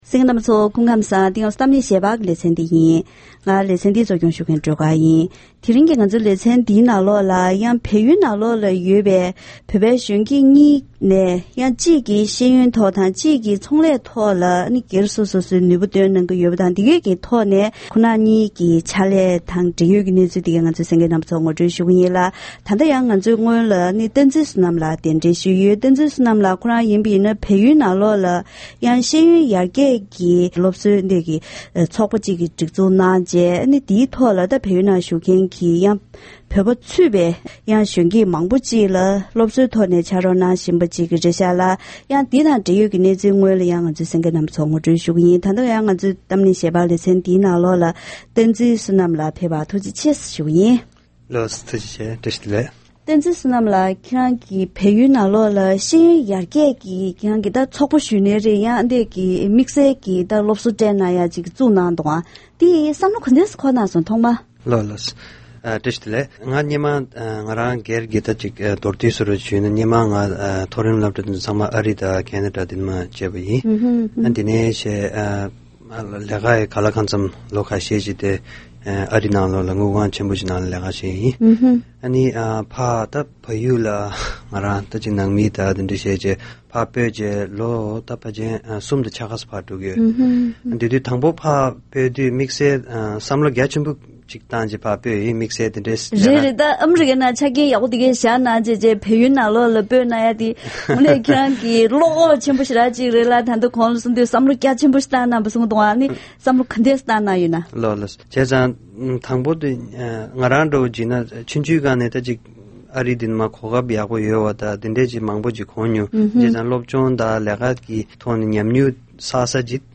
༄༅། །ཐེངས་འདིའི་གཏམ་གླེང་ཞལ་པར་ལེ་ཚན་ནང་བལ་ཡུལ་ནང་ཡོད་པའི་བོད་པའི་གཞོན་སྐྱེས་གཉིས་ཀྱིས་ཤེས་ཡོན་སློབ་གསོ་དང་། བོད་པའི་རྒྱན་ཆའི་ཚོང་གཉེར་ཐོག་དོ་སྣང་ཆེན་པོས་རང་ནུས་བཏོན་བཞིན་ཡོད་པའི་སྐོར་ལ་བཀའ་མོལ་ཞུས་པ་ཞིག་གསན་རོགས་གནང་།